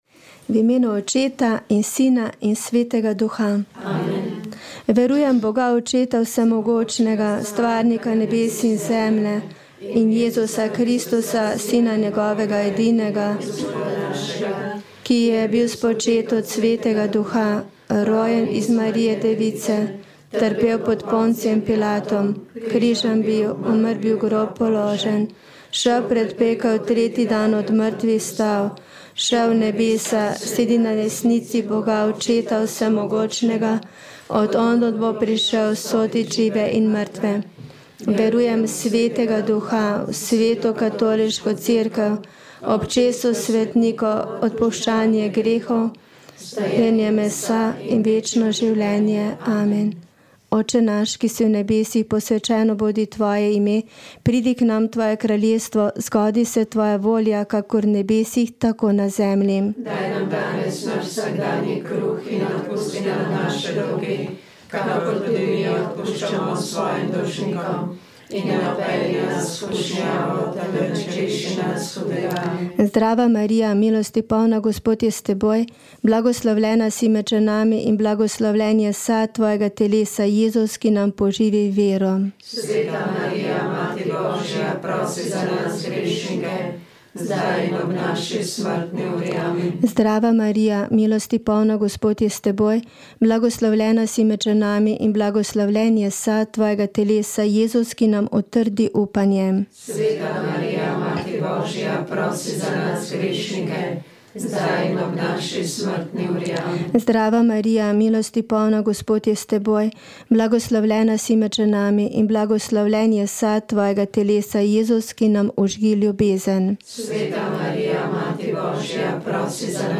Rožni venec
Molile so redovnice - Marijine sestre.